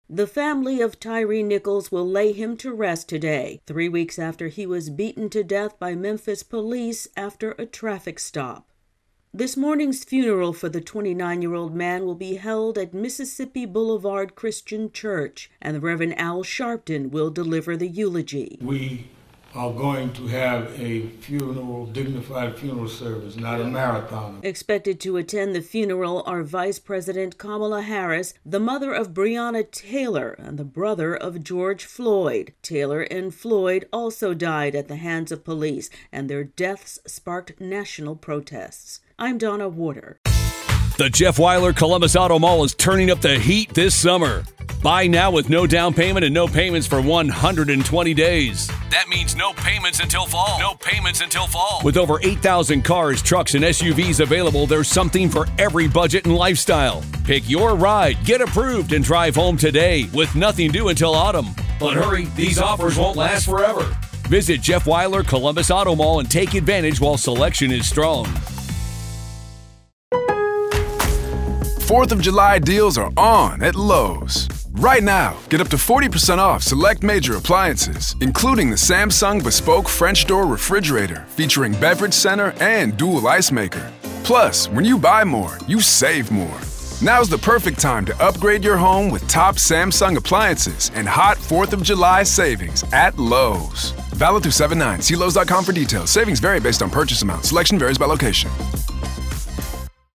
reports on a funeral service in Memphis today.